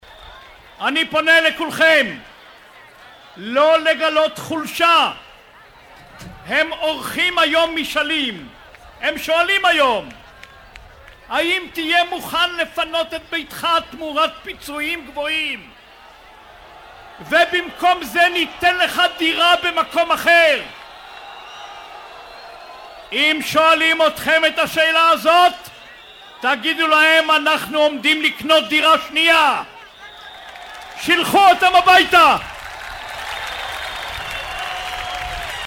קטעים נוסטלגיים נוספים של ראש-הממשלה ויוזם מסע ההתנתקות אריאל שרון, בעצרות הימין השונות.